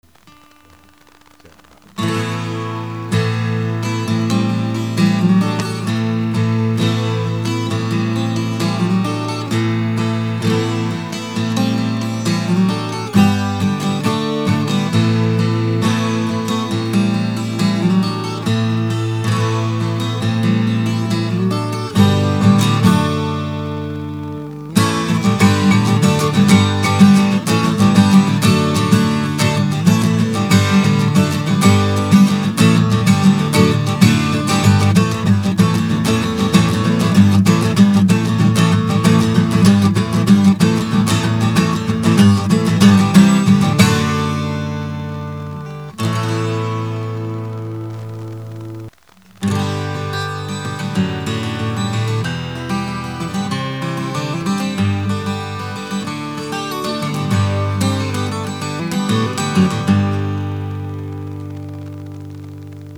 12 cordes
Comme convenu, voici un cht'it sample que j'ai enregistré (total unplugged) avec ma Takamine EN1012.
Mes cordes : Ernie Ball Phosphore Studio Bronze Light
Et encore, j'ai enregistré avec un micro Shure SM58 (pas l'idéal pour la guitare..). Micro planté directement dans la carte son du PC, aucun traficotage, pas de compression, rien de rien.
Désolé pour les grésillements, c'est mon cable micro qui est foutu